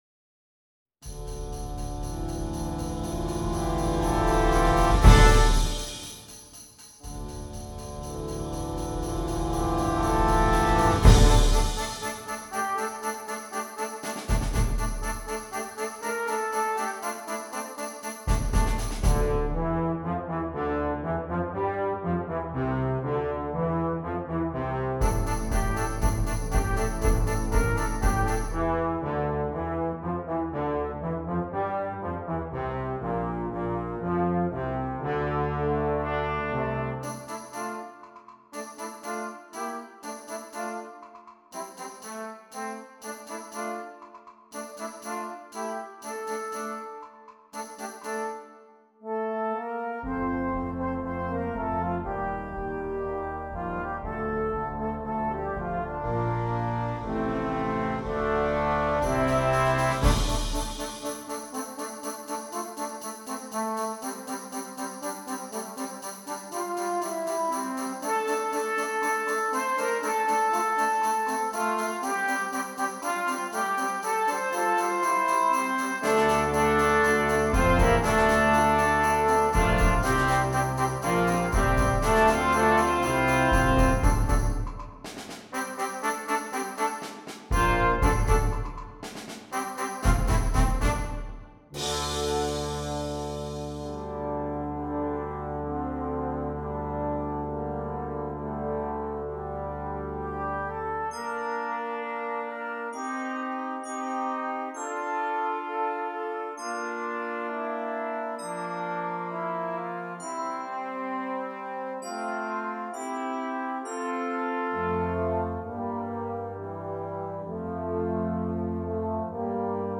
Brass Band
Difficulty: Easy Order Code
Flexible Junior Brass Band Series